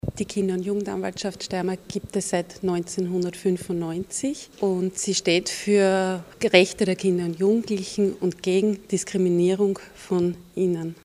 Kinder- und Jugendanwältin Brigitte Pörsch: